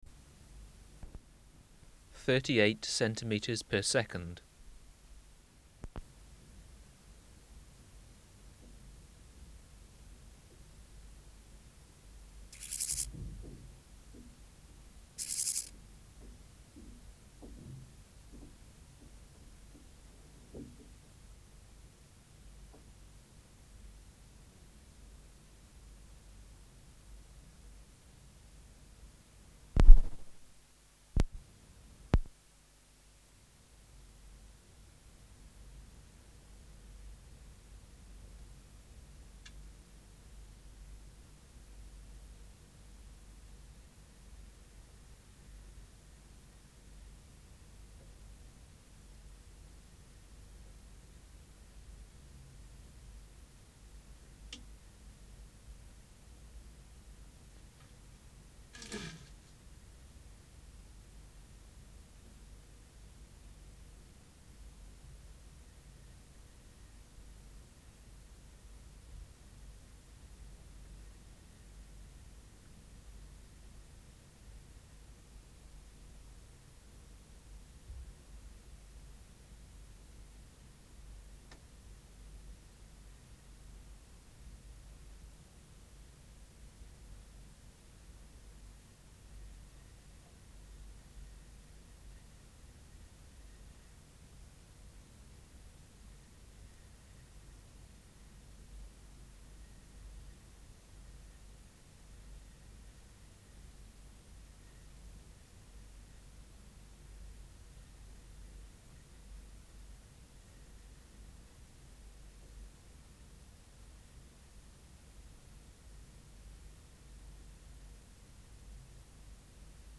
Project: Natural History Museum Sound Archive Species: Chorthippus (Glyptobothrus) jacobsi